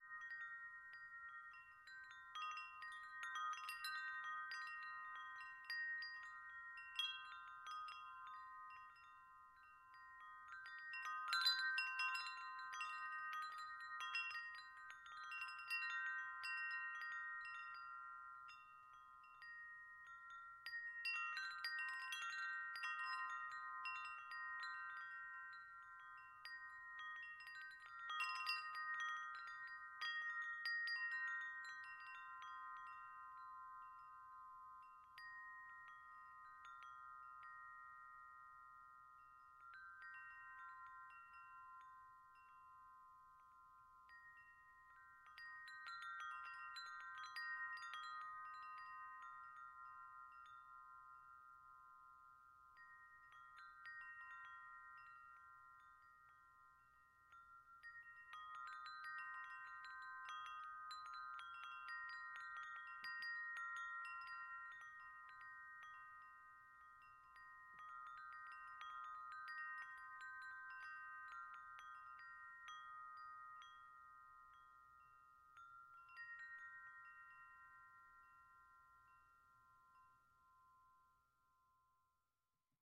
Звук падающего снега похож на колокольчики